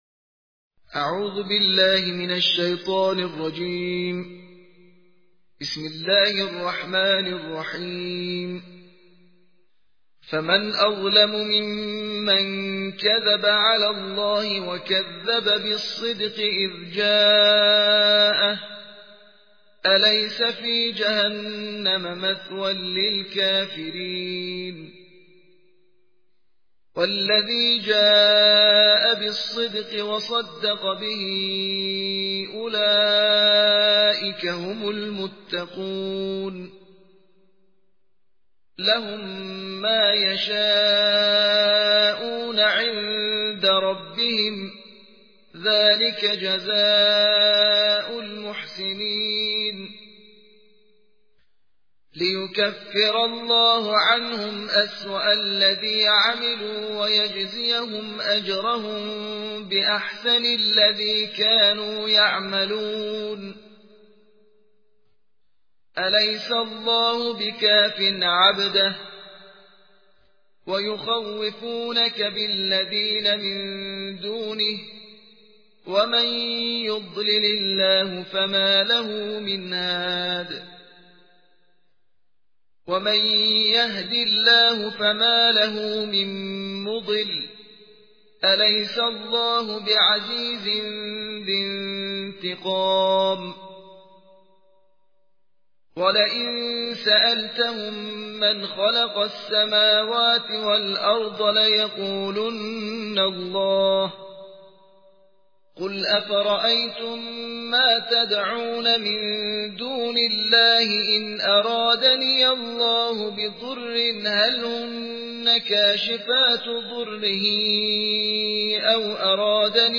ترتیل جزء بیست‌وچهارم قرآن
قرائت قرآن